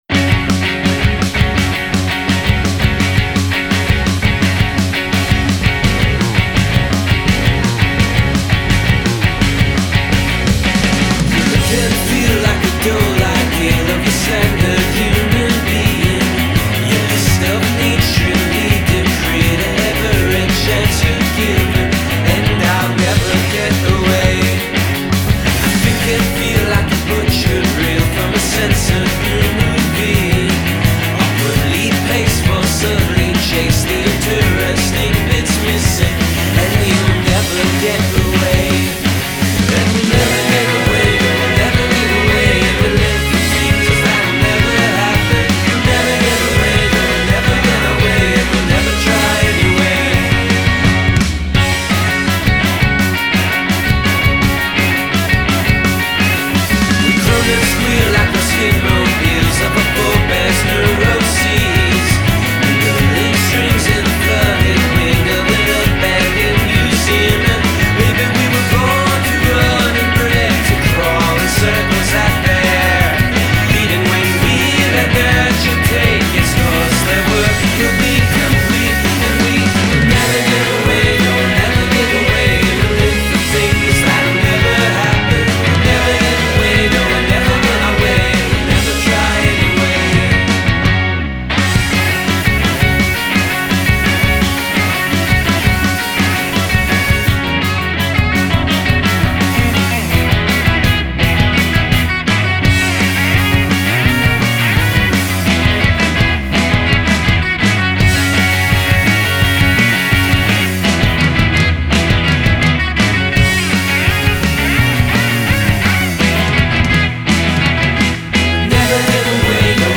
things also get more melodically serious